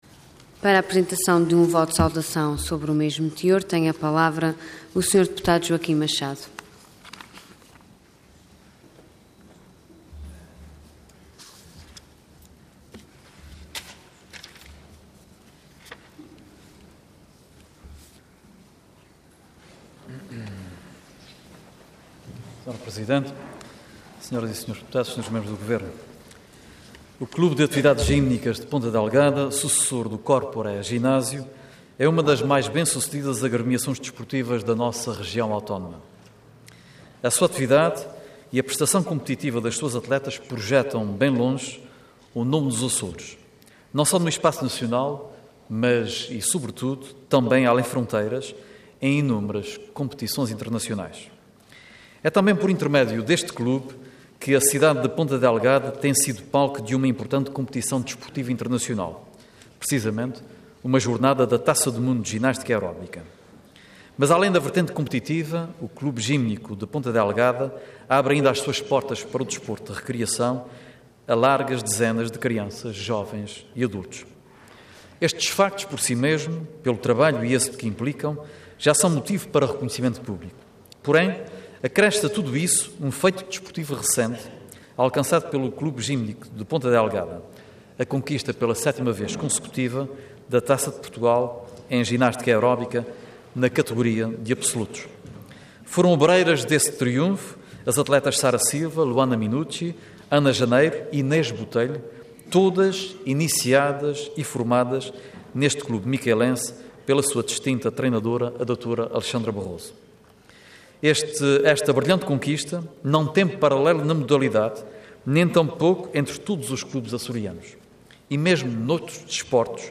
Intervenção Voto de Saudação Orador Joaquim Machado Cargo Deputado Entidade PSD